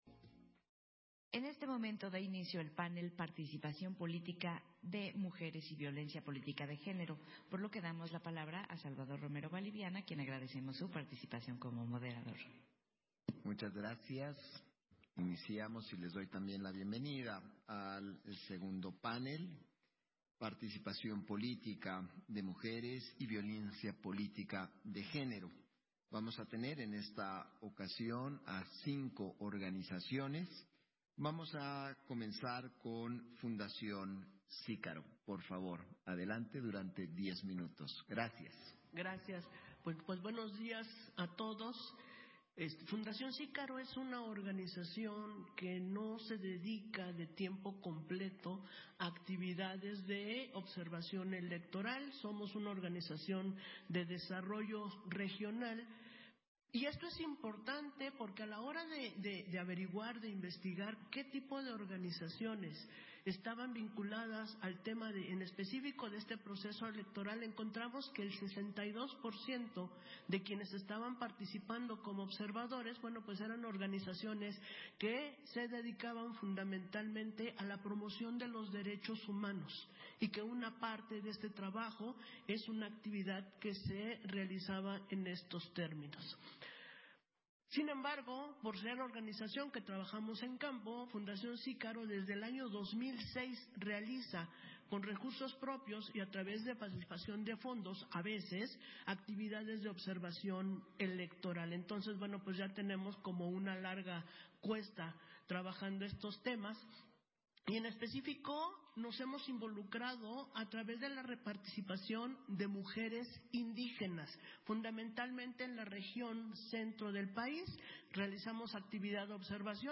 Segundo panel, Participación política de mujeres y violencia política de género, en el marco de la Presentación de hallazgos, conclusiones y recomendaciones de la Observación Electoral Nacional